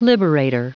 Prononciation du mot liberator en anglais (fichier audio)
Prononciation du mot : liberator